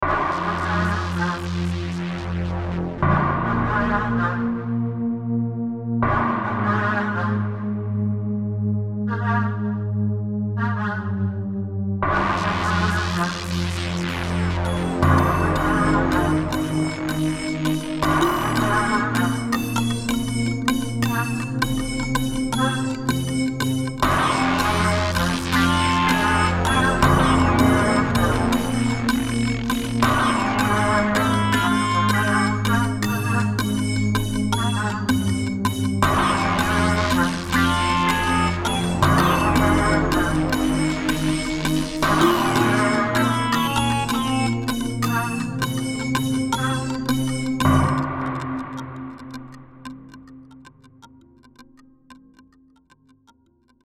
Musique pour le théâtre